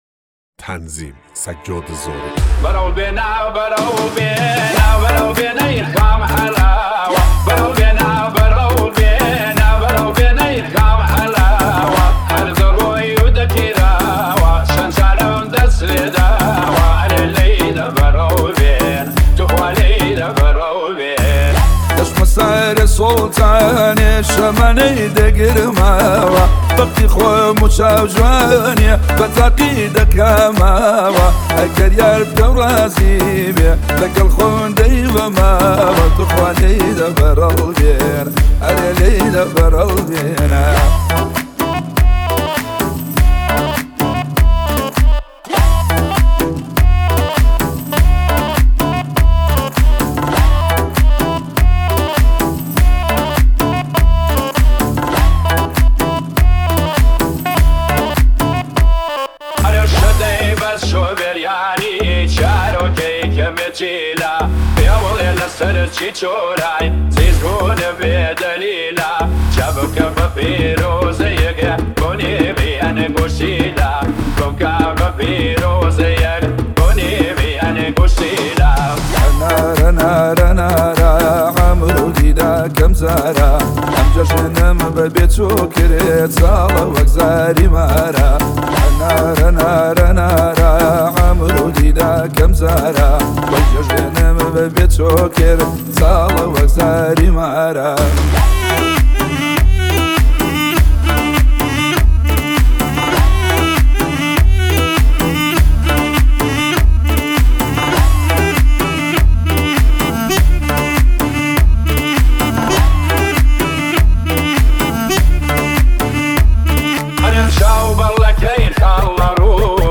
891 بازدید ۱۹ اردیبهشت ۱۴۰۳ هوش مصنوعی , آهنگ کردی